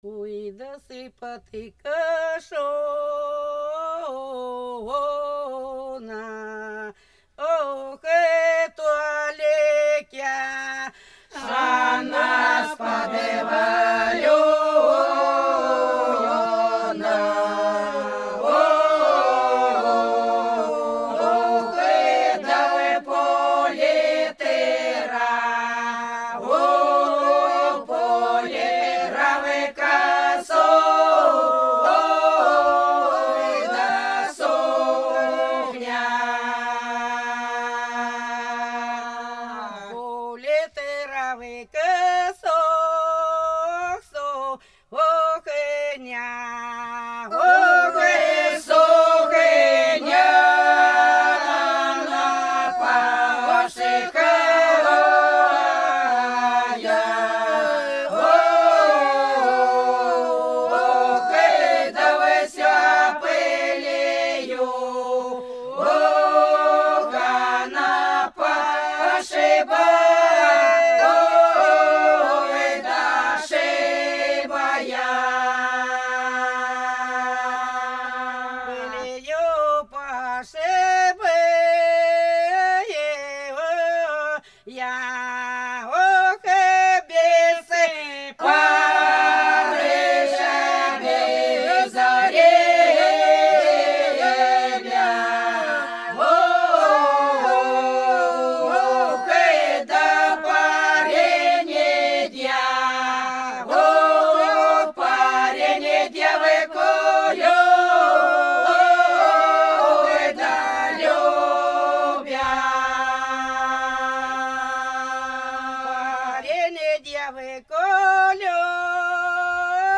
Пролетели все наши года Вот подкошена, она сподвалена – протяжная (Фольклорный ансамбль села Иловка Белгородской области)
02_Вот_подкошена,_она_сподвалена_–_протяжная.mp3